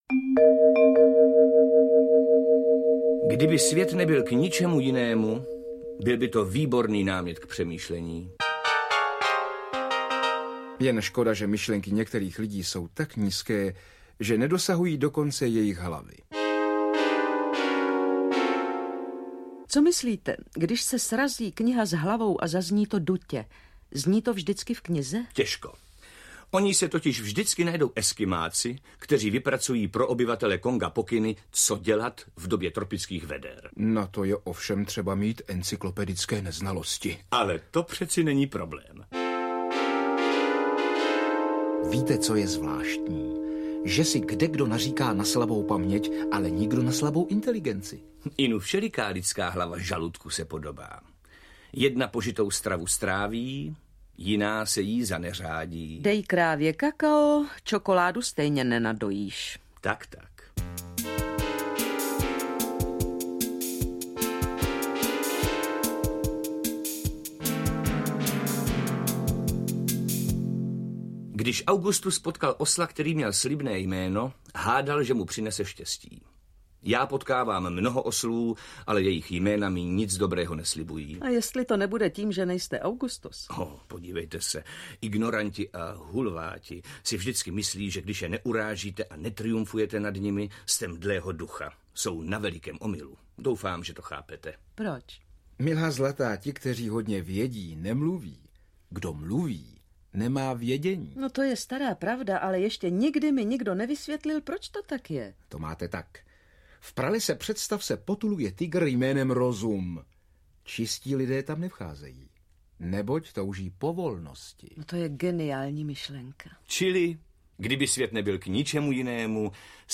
Audio kniha
Ukázka z knihy
• InterpretJaroslava Adamová, František Němec, Petr Kostka